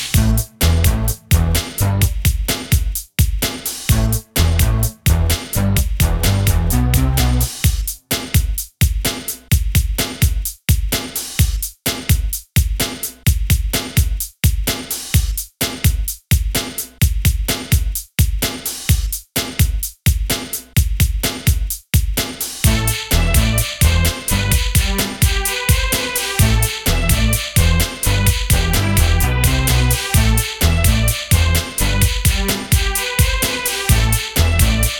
Жанр: Хип-Хоп / Рэп / R&B / Соул